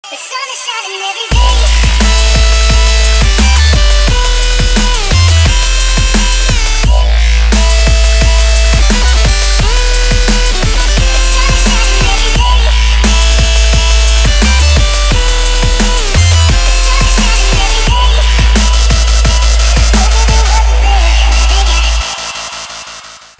• Качество: 320, Stereo
Dubstep
drum&bass